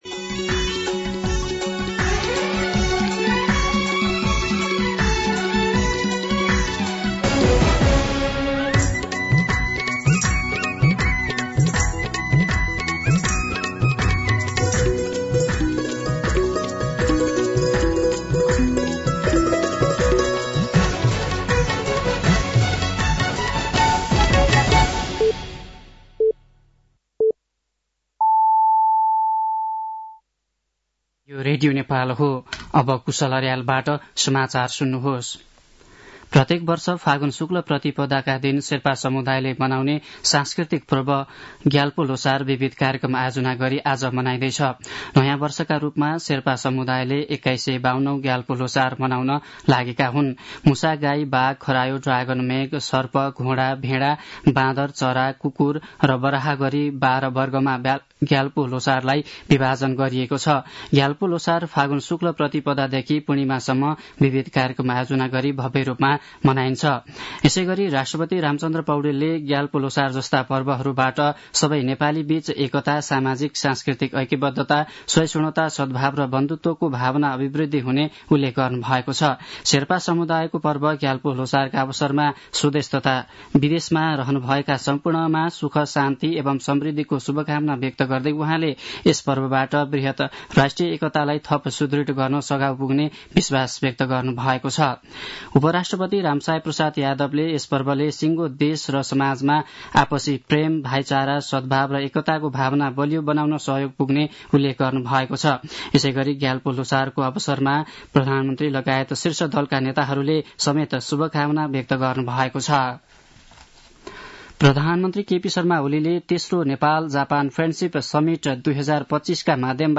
दिउँसो ४ बजेको नेपाली समाचार : १७ फागुन , २०८१
4-pm-Nepali-News-4.mp3